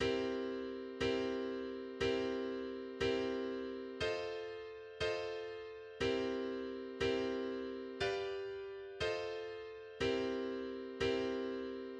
A simple blues progression, in C, is as follows: